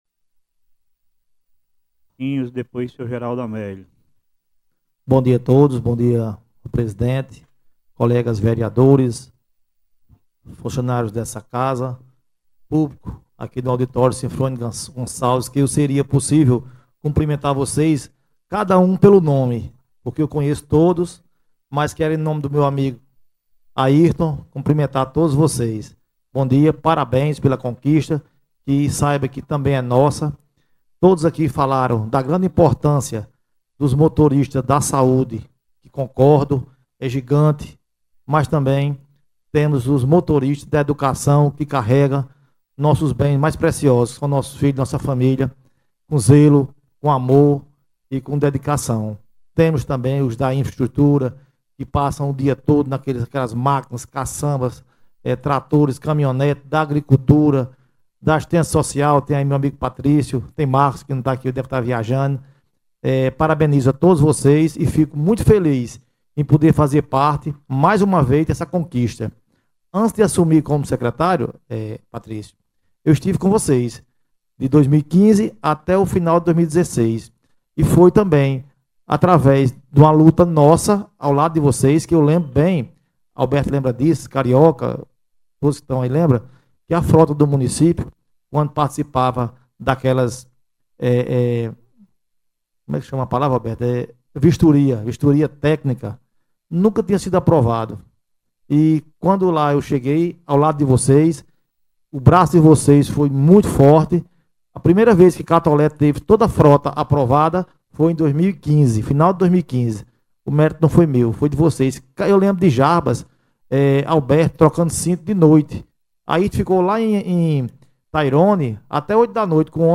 Durante a sessão especial realizada no Auditório Sinfônico Gonçalves, o vereador Luciano Maia fez um pronunciamento marcado por reconhecimento, gratidão e memórias de luta ao lado dos motoristas das diversas…